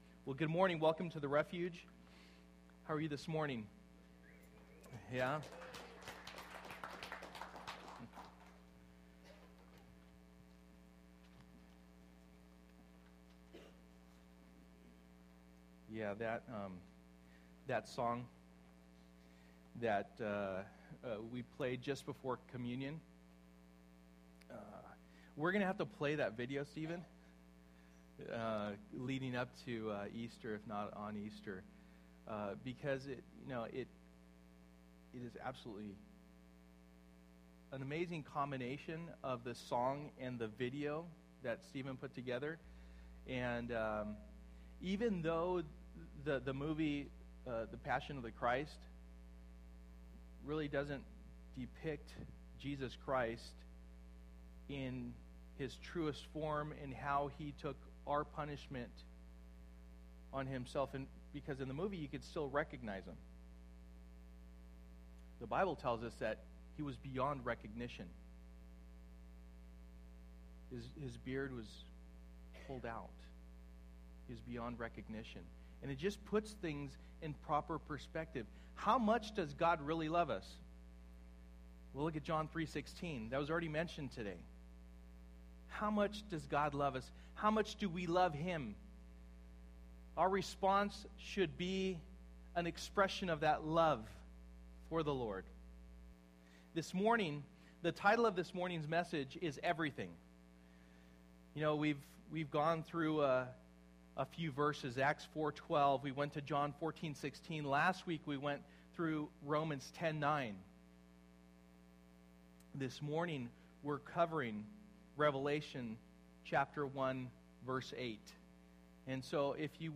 No Other Name Passage: Revelation 1:1-8 Service: Sunday Morning %todo_render% « No Other Name